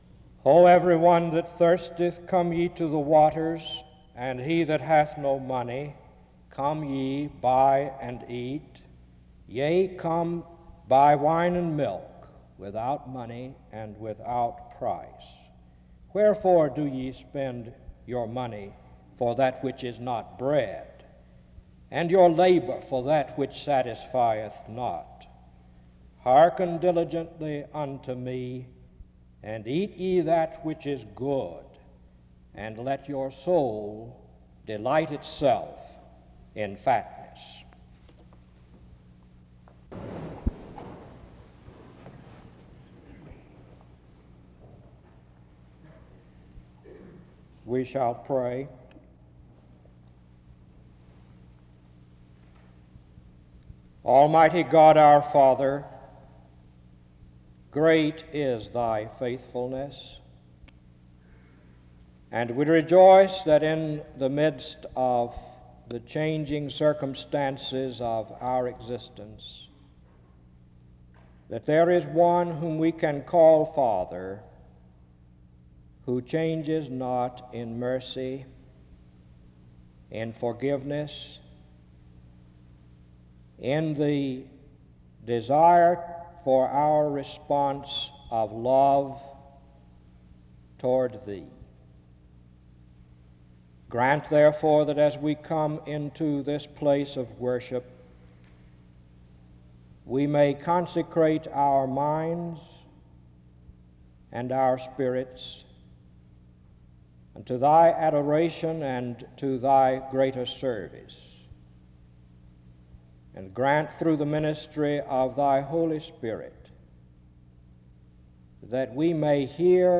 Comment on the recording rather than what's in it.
The service begins with the reading of Isaiah 55:1-2 (00:00-00:38), prayer (00:47-03:37), and the reading of 2 Peter 1:1-11 (03:48-06:00). In Collection: SEBTS Chapel and Special Event Recordings SEBTS Chapel and Special Event Recordings